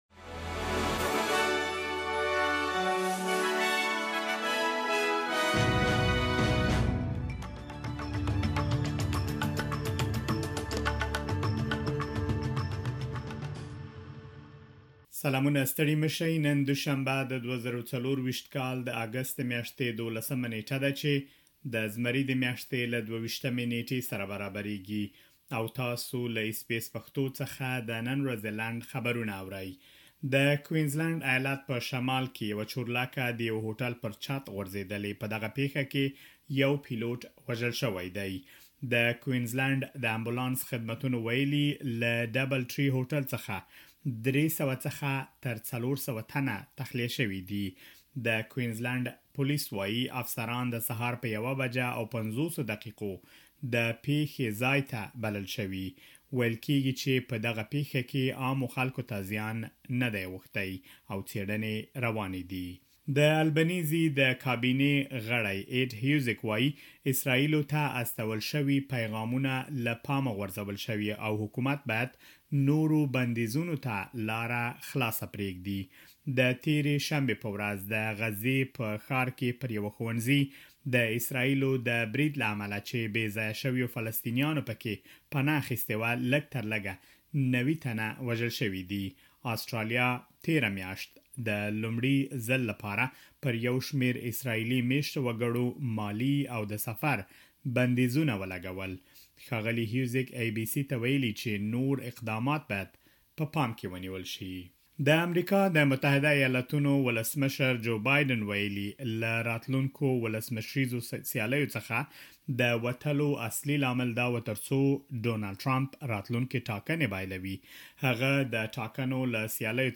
د اس بي اس پښتو د نن ورځې لنډ خبرونه|۱۲ اګسټ ۲۰۲۴